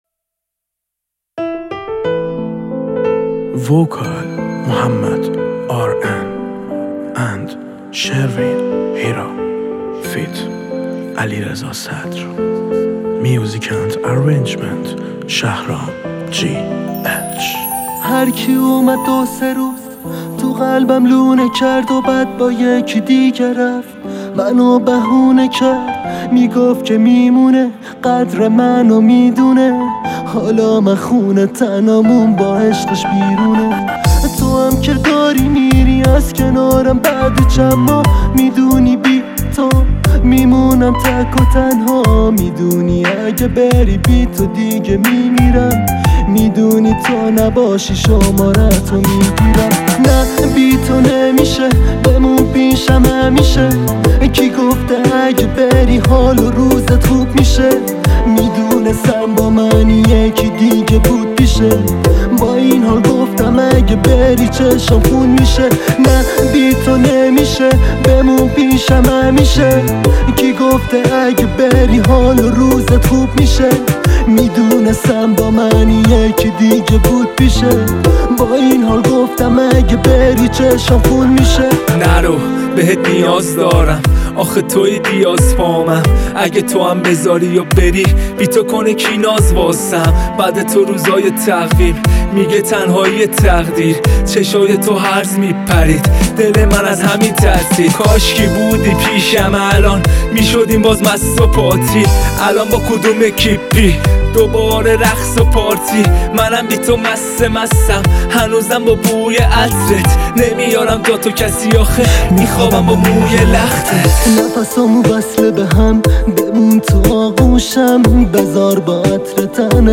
آهنگ رپ